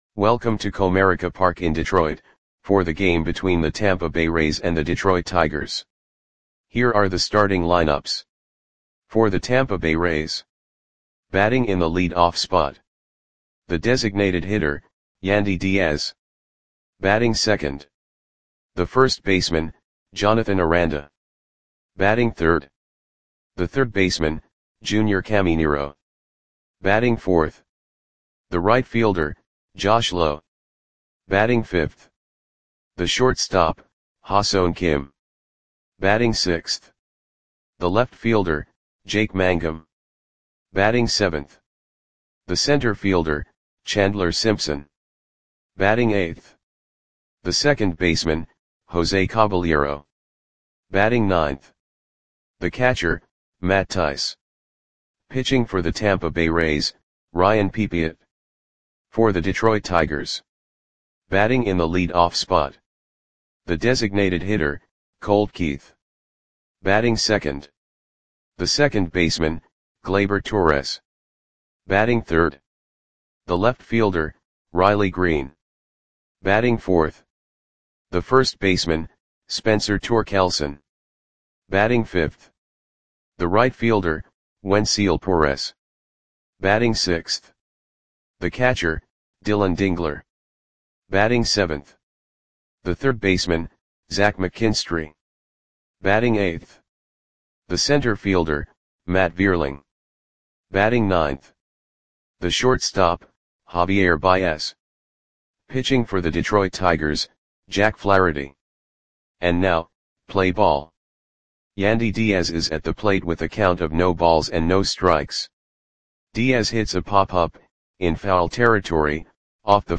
Audio Play-by-Play for Detroit Tigers on July 8, 2025
Click the button below to listen to the audio play-by-play.